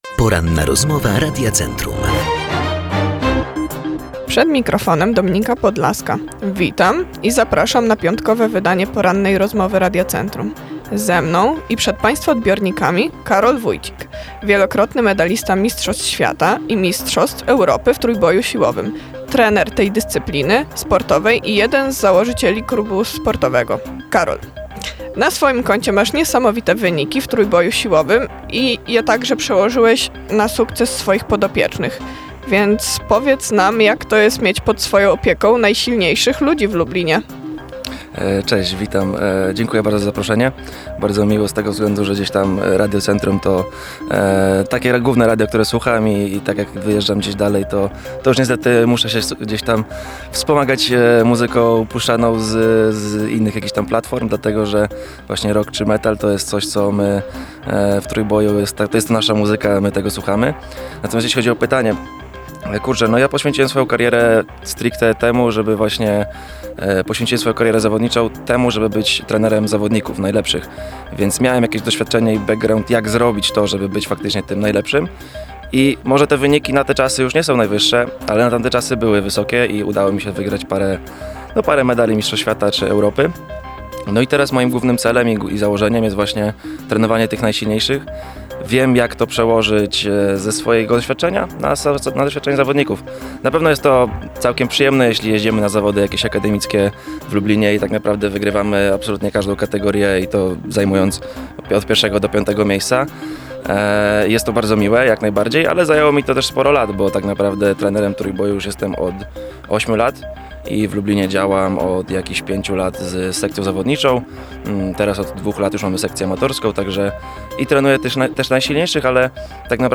ROZMOWA-2.mp3